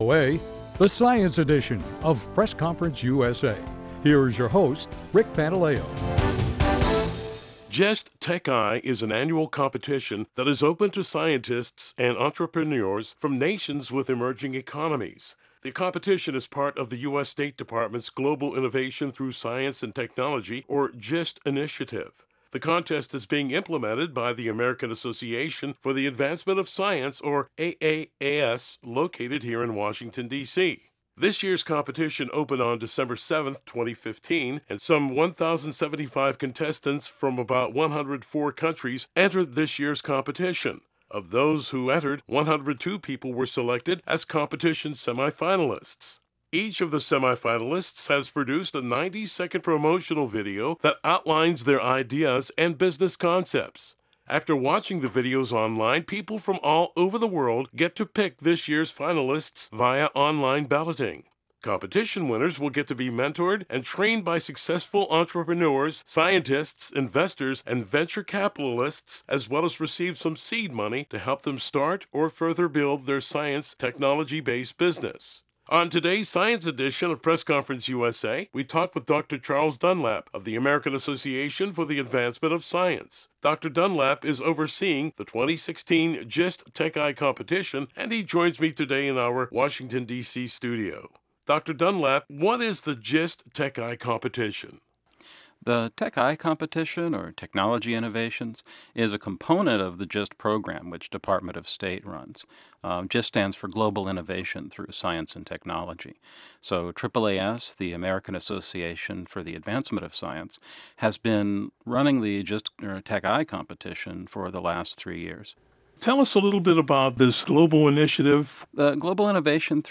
talks with a competition organizer as well as past winners and a current finalist about this international challenge.